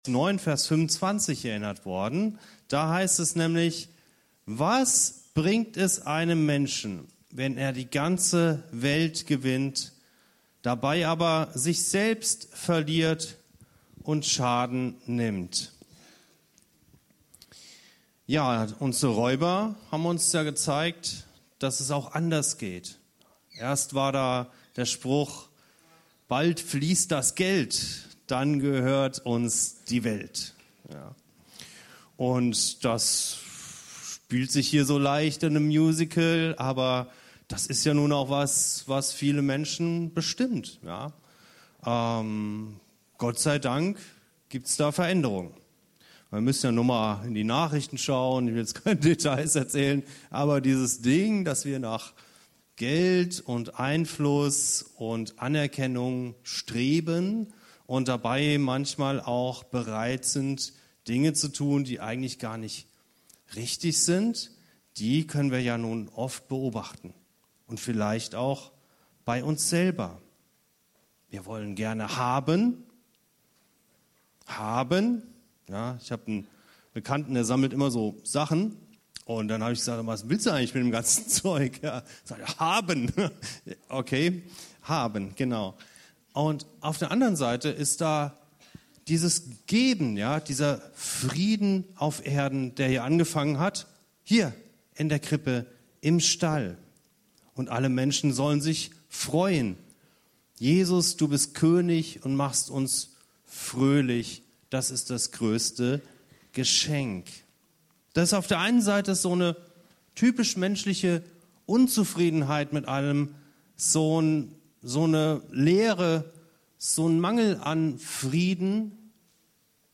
Die Räuber von Bethlehem - Predigt zum Weihnachtsmusical